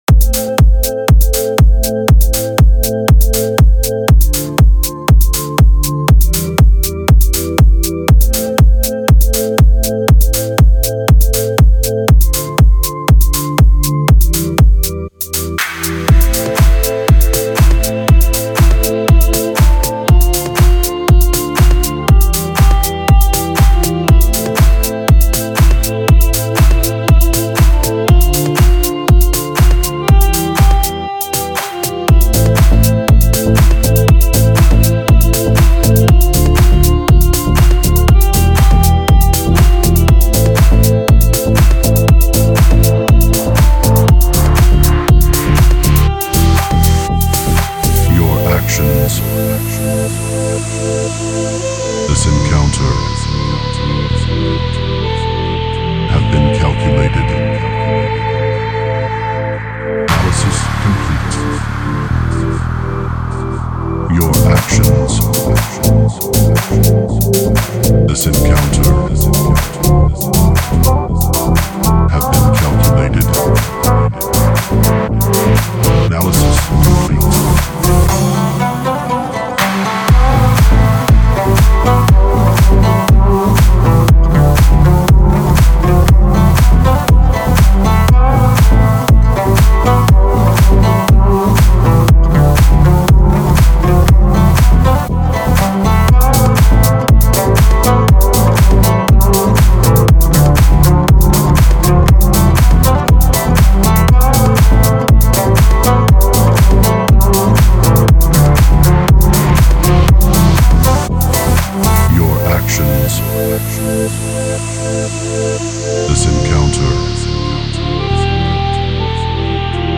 это завораживающий трек в жанре электроника